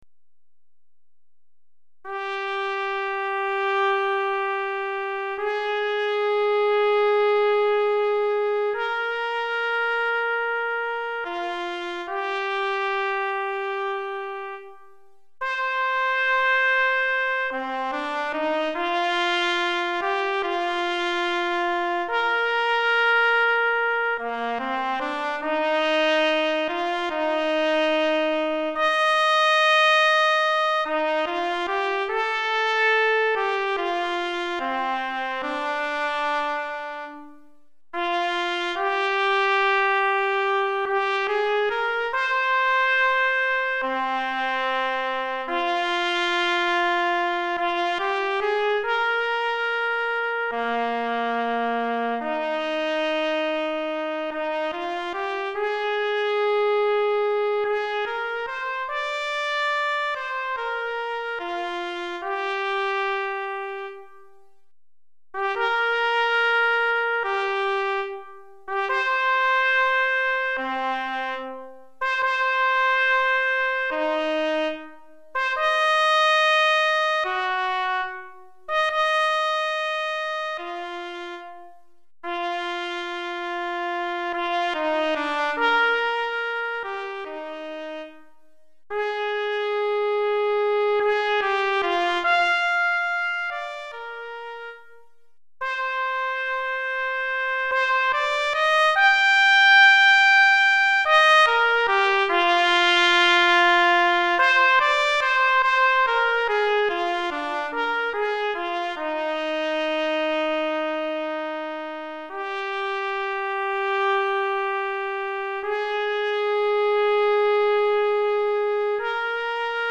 Trompette Solo